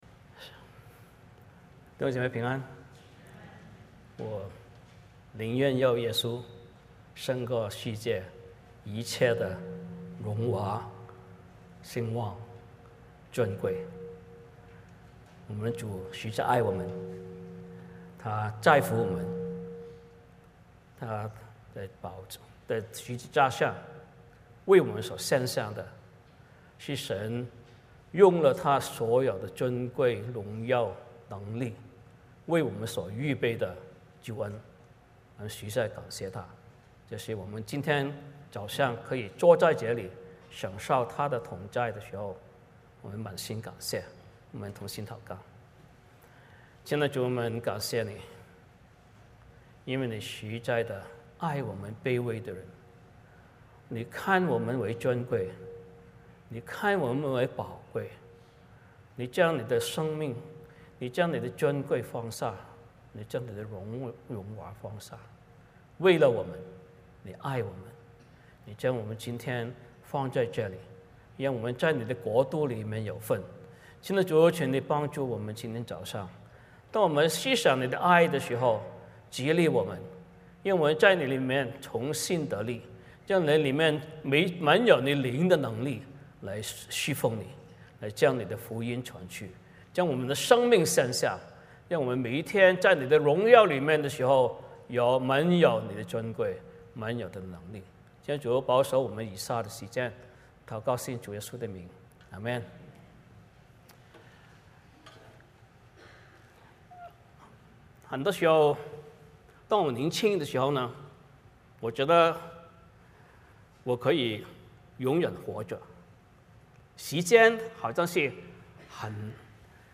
彼得前书 4:7-19 Service Type: 主日崇拜 欢迎大家加入我们的敬拜。